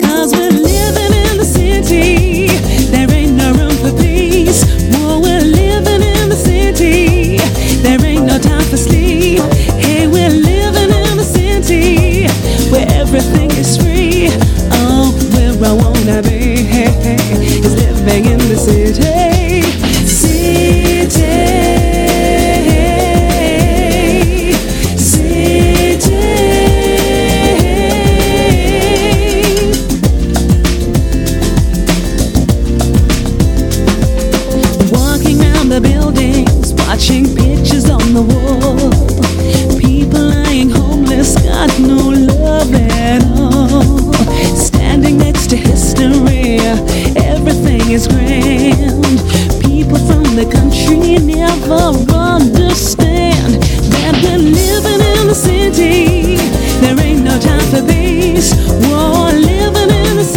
SOUL / SOUL / FREE SOUL / FRENCH (FRA)
大合唱のコーラス・フック炸裂でグルーヴィーな
キュートな子供コーラス入りの